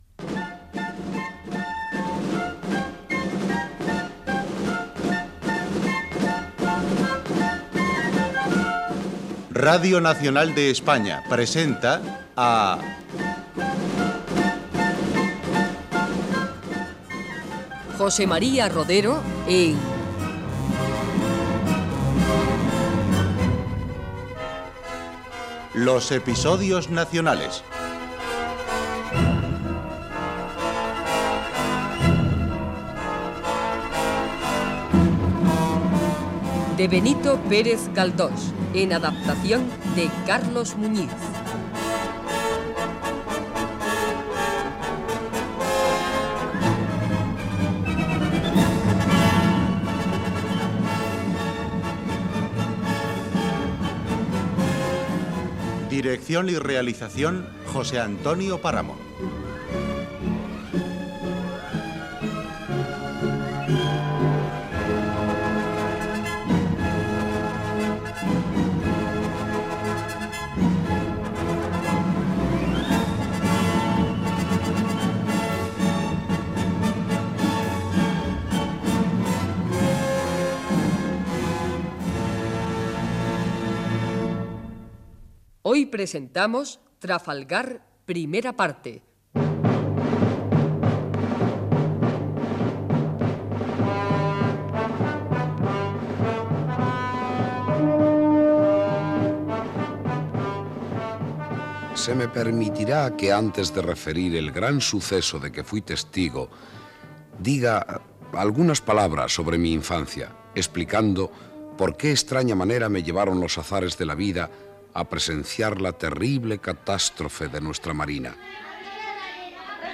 Adaptació radiofónica de "Los episodios nacionales" de Benito Pérez Galdós.
Careta de la sèrie, el personatge gadità narrador explica la seva infantesa i com va començar a treballar de patge Gènere radiofònic Ficció